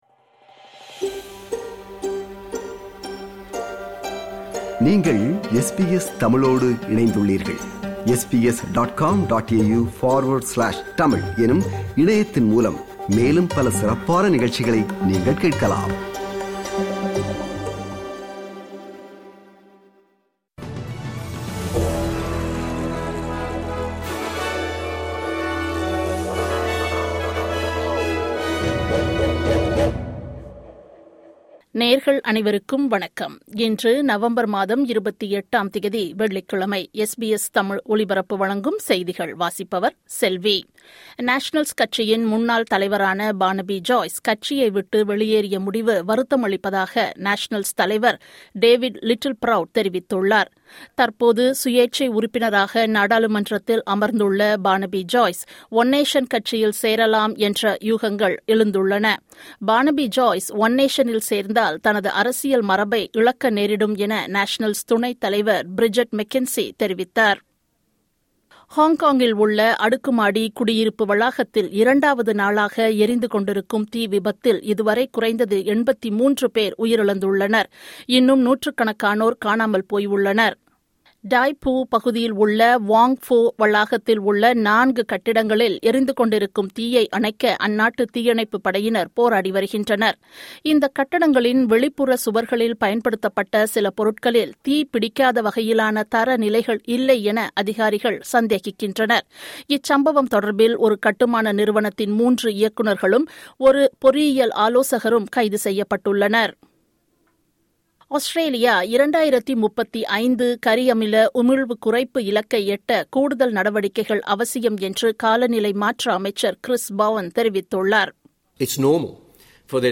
இன்றைய செய்திகள்: 28 நவம்பர் 2025 - வெள்ளிக்கிழமை
SBS தமிழ் ஒலிபரப்பின் இன்றைய (வெள்ளிக்கிழமை 28/11/2025) செய்திகள்.